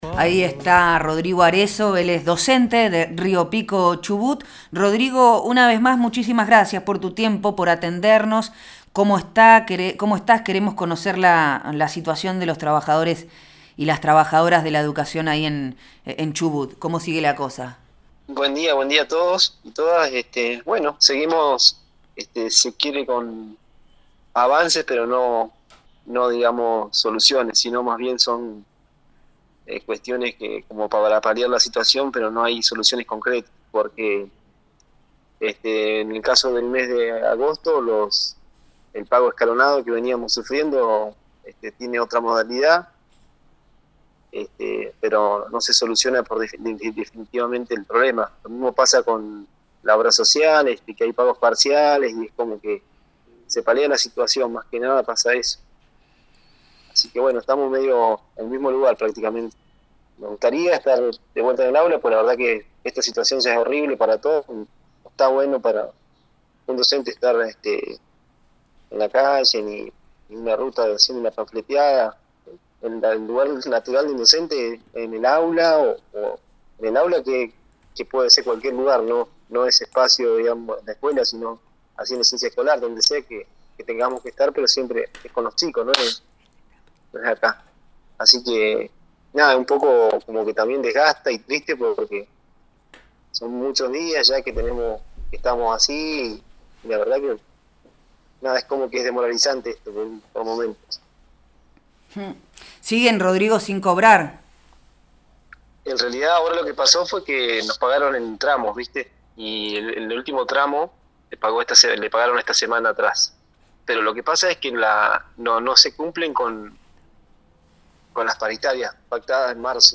en diálogo con Proyecto Erre habló sobre la situación de las y los trabajadores de la educación de esa provincia. El reclamo en las rutas y las amenzas por parte del gobierno provincial.